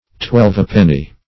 Twelvepenny \Twelve"pen*ny\, a.
twelvepenny.mp3